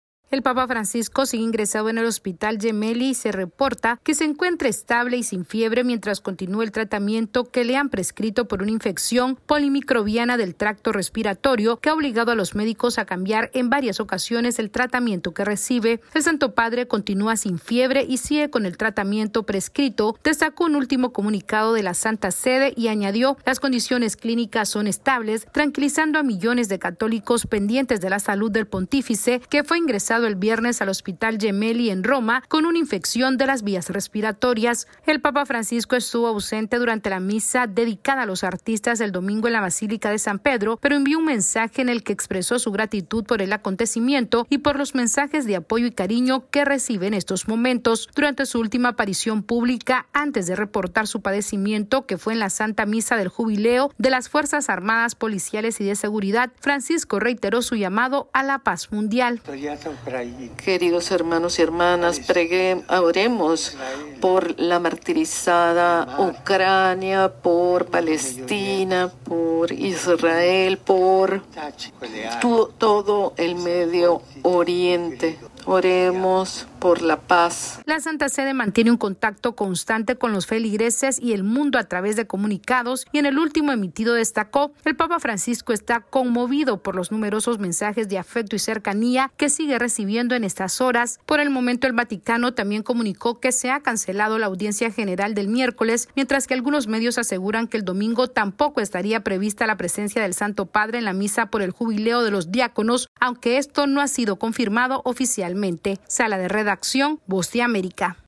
AudioNoticias
El Vaticano informó que el papa Francisco continúa estable y sin fiebre luego de haber sido internado en el hospital por una infección en su sistema respiratorio. Esta es una actualización de nuestra Sala de Redacción.